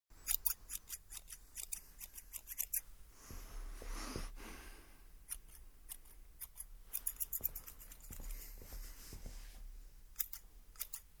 دانلود آهنگ قیچی - سه بعدی از افکت صوتی طبیعت و محیط
دانلود صدای قیچی - سه‌بُعدی از ساعد نیوز با لینک مستقیم و کیفیت بالا
جلوه های صوتی